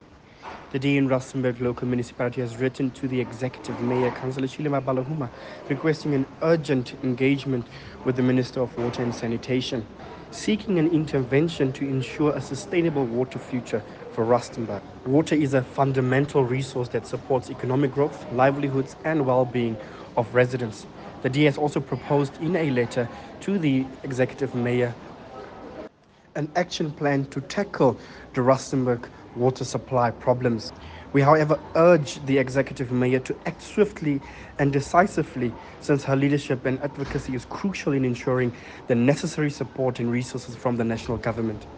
Issued by Cllr Luan Snyders – DA Councillor: Rustenburg Local Municipality
Note to Broadcasters: Please find a linked soundbite in
English by Cllr Luan Snyders.